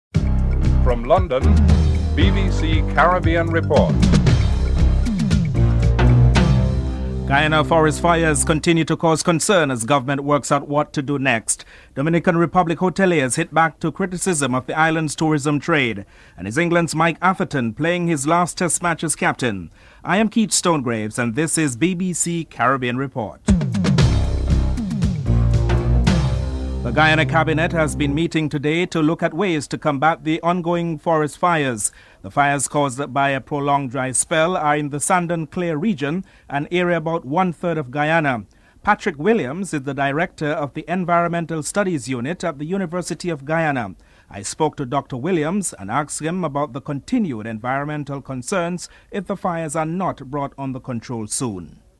Jamaican Ambassador Richard Bernal comments on the issue (07:47-10:22)